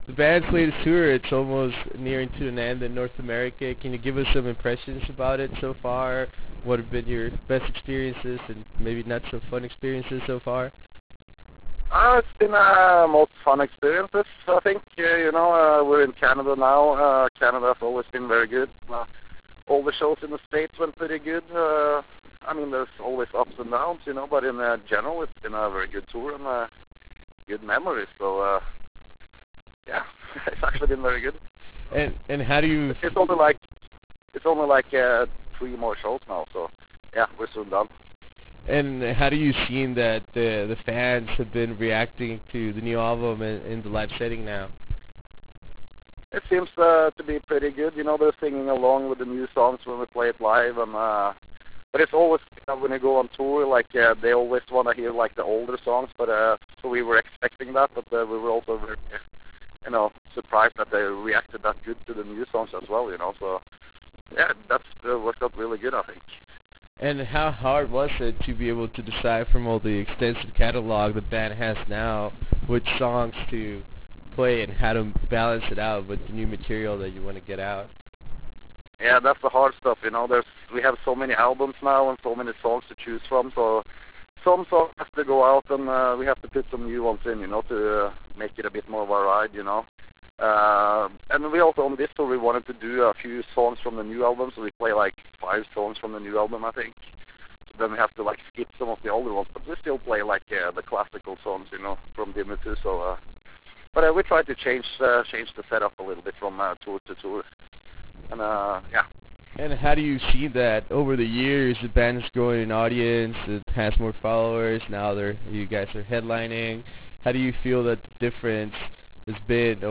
Interview with Dimmu Borgir - Galder
Days before completing their North American tour, we managed to have a conversation with Dimmu Borgir’s guitarist Galder. In this 17 minute interview we discuss about the band’s impression of their tour and their latest epic release “Abrahadabra”. We also talk about future touring plans and what’s next in the band’s agenda.